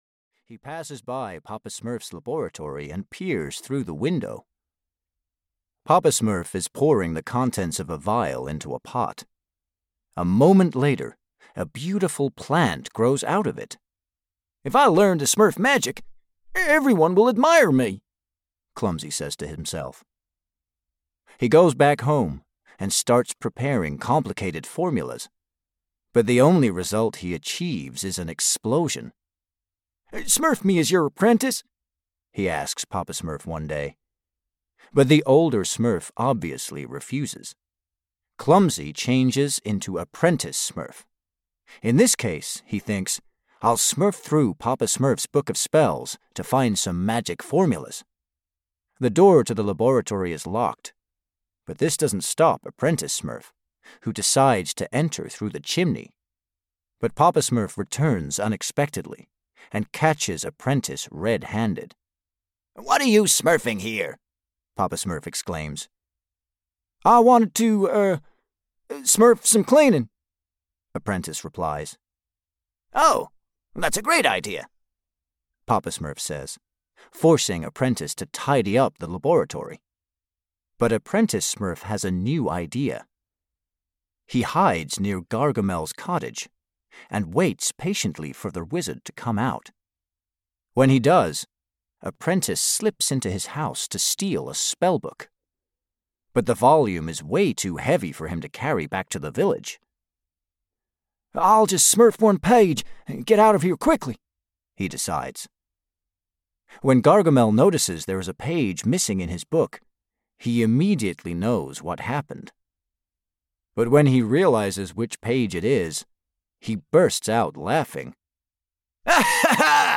Audio knihaSmurfs: Storytime Collection 3 (EN)
Ukázka z knihy